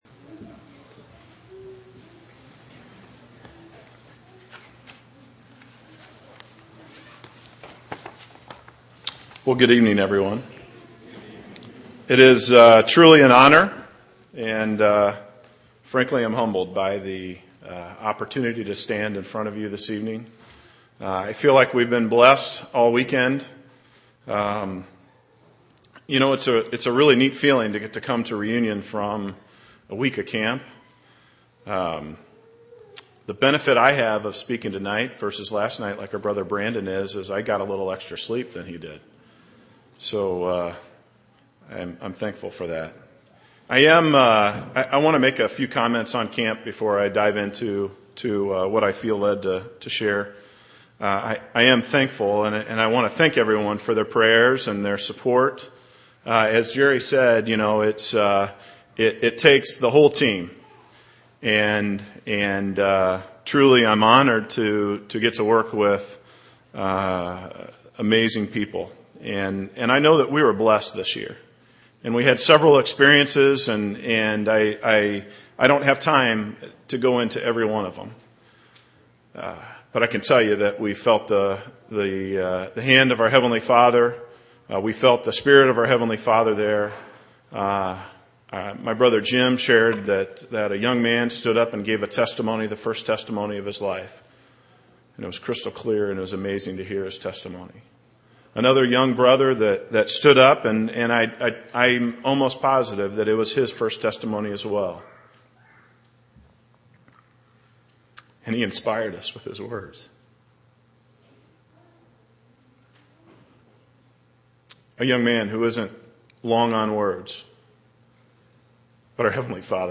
Missouri Reunion Event: Missouri Reunion